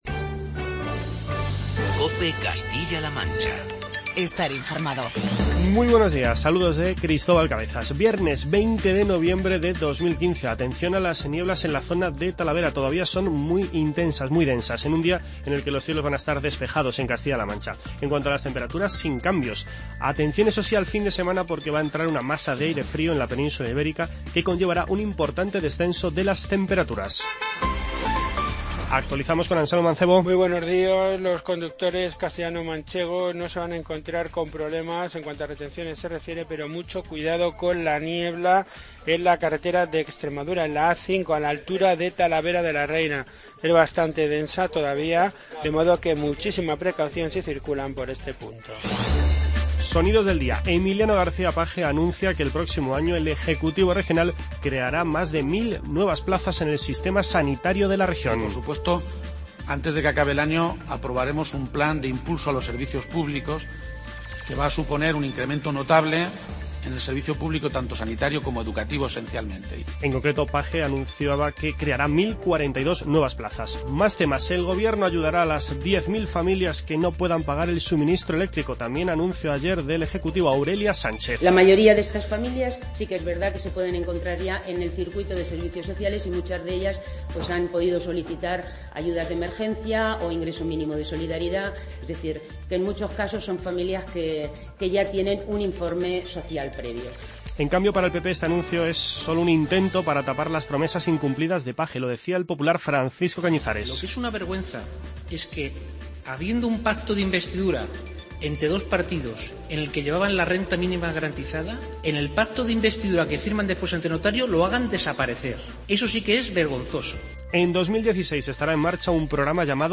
Informativo regional y provincial